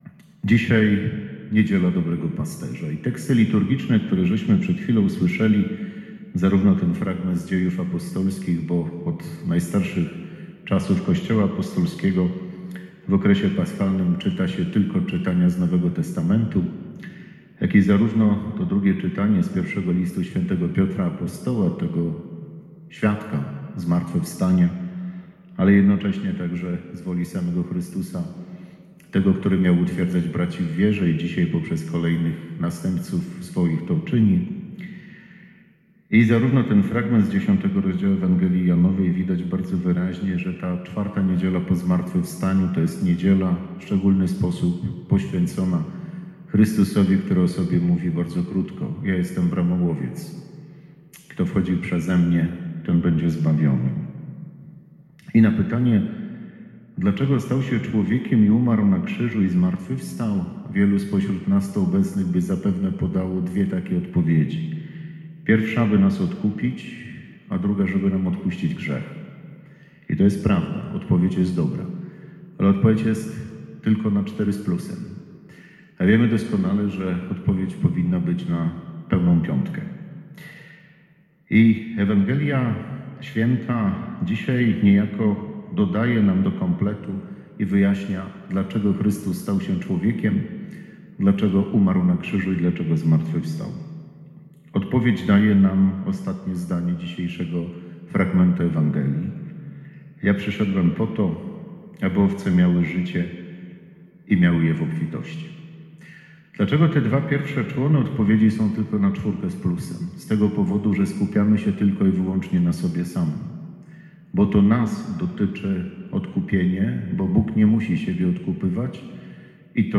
Homilia